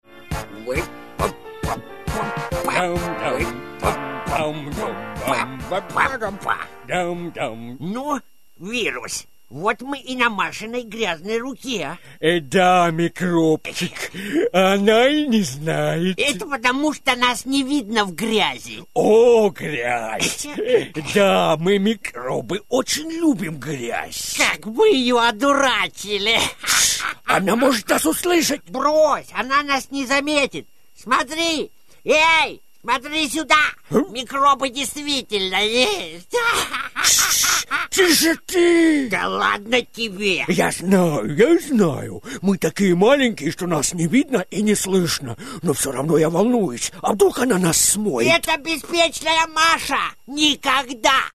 RUSSISCHER PROFI-SPRECHER, Hochrussisch, Akzentfrei, Muttersprache.
Sprechprobe: Sonstiges (Muttersprache):
russian voice over artist